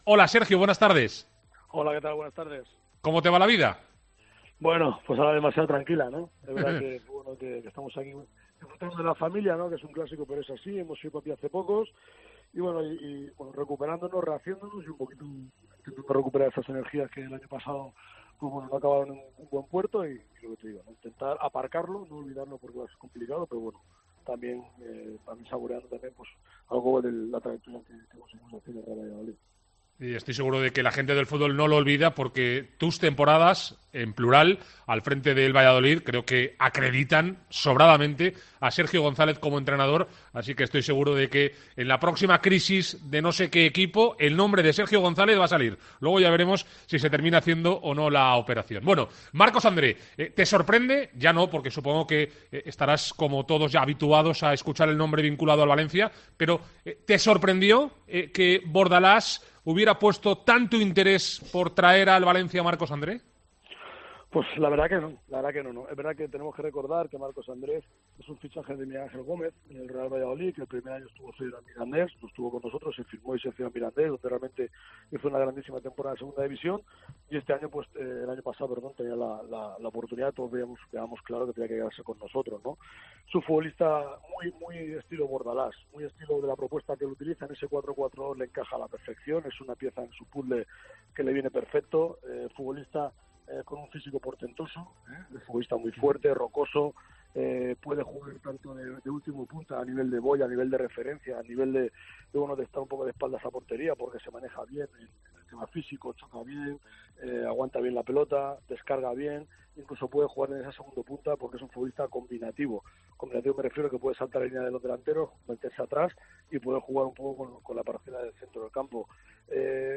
AUDIO. Entrevista a Sergio González en Deportes COPE Valencia